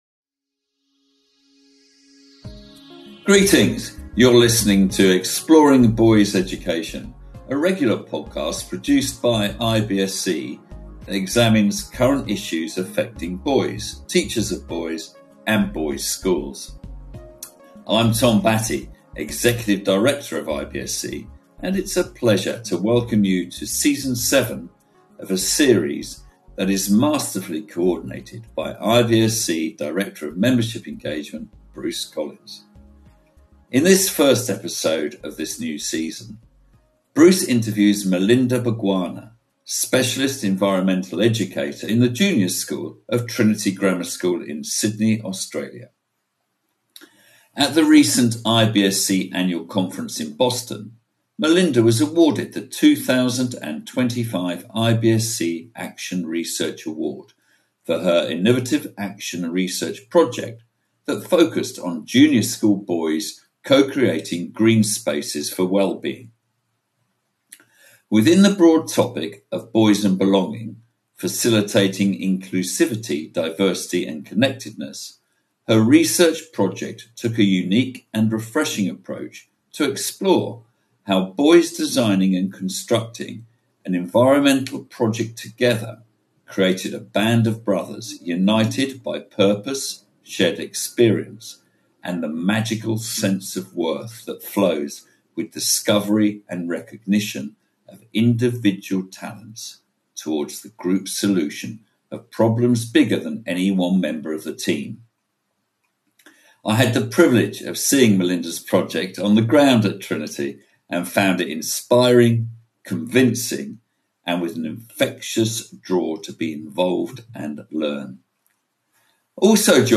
Compiled from one-on-one and roundtable interviews at NYCC, SDCC, and a special New York Mars event, this episode explores the intersection of science fiction and science fact through the lens of one of television’s … … continue reading